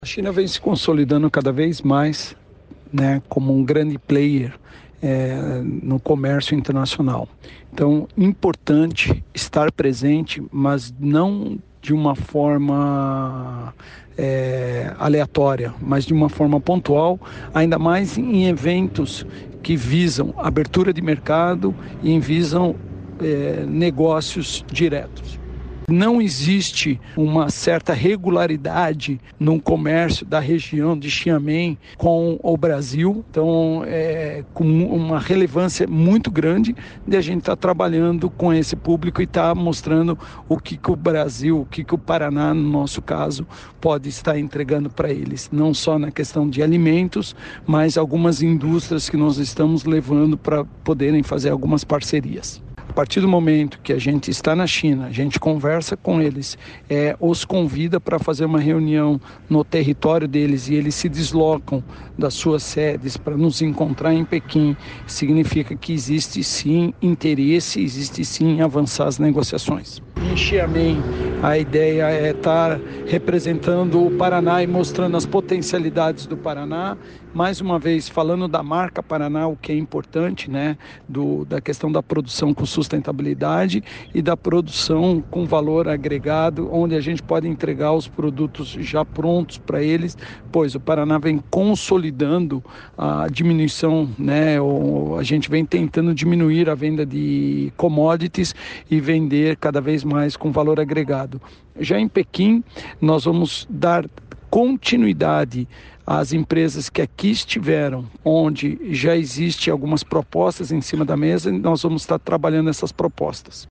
Sonora do presidente da Invest Paraná, Eduardo Bekin, sobre a missão do Paraná à China em busca de investimentos